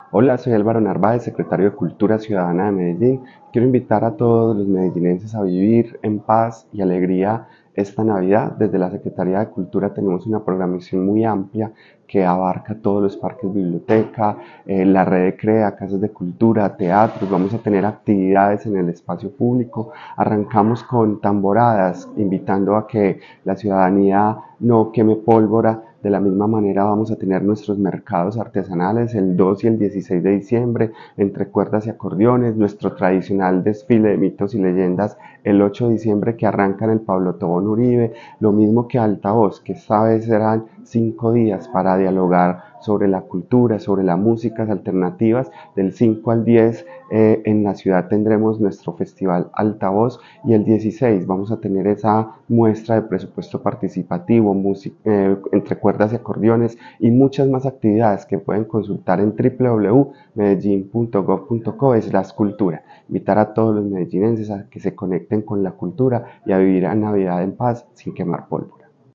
Palabras de Álvaro Narváez Díaz, secretario de Cultura Ciudadana.